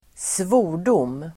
Ladda ner uttalet
Uttal: [²sv'o:r_dom:]
svordom.mp3